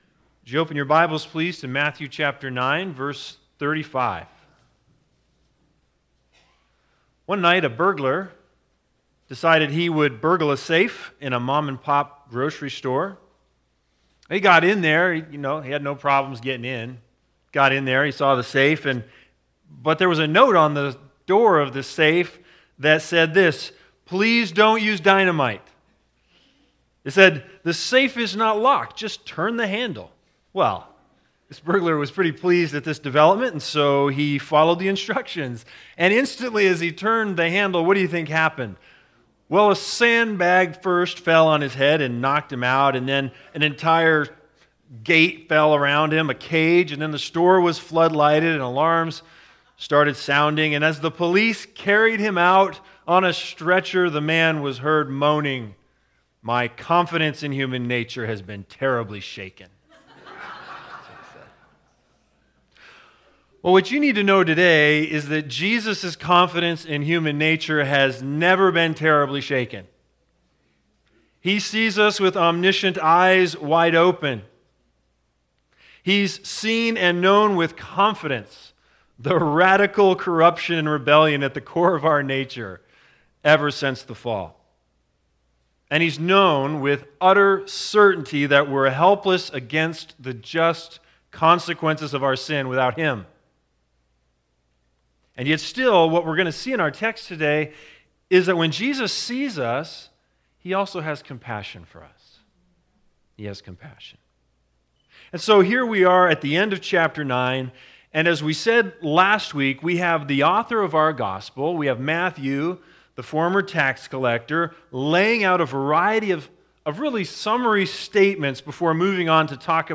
Matthew 9:35-38 Service Type: Sunday Sermons Big Idea